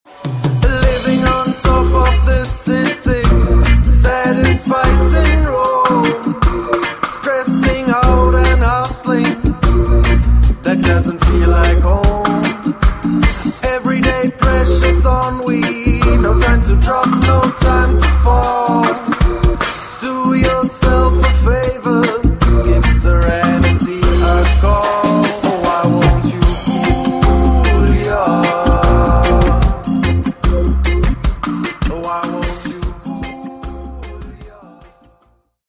hinzu kommt ein Mix aus zwei Vocals.
Roots-Reggae-Gewand.